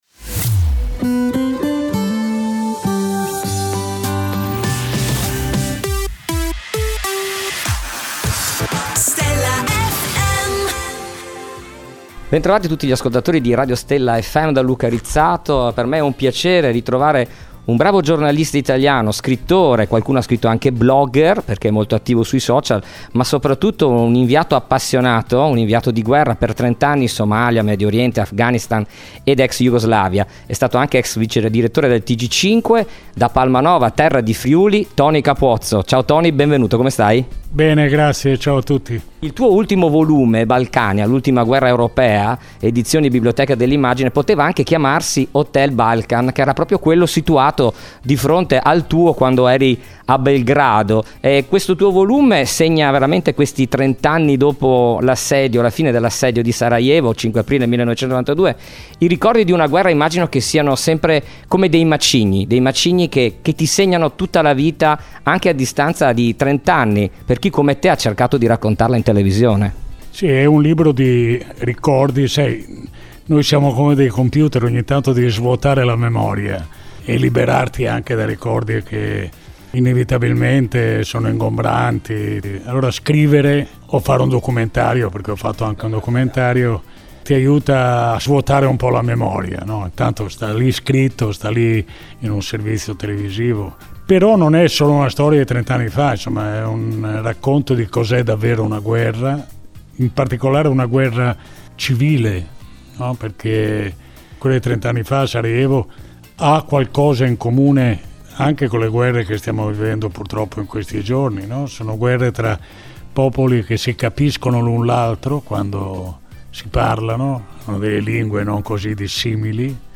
Intervista I Toni Capuozzo | Stella FM
Intervista esclusiva dell’inviato per Stella FM a Toni Capuozzo.